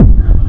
• Industrial Techno Kick oneshot SC - F.wav
Nicely shaped, crispy and reverbed industrial techno kick, used for hard techno, peak time techno and other hard related genres.
Industrial_Techno_Kick_oneshot_SC_-_F__joA.wav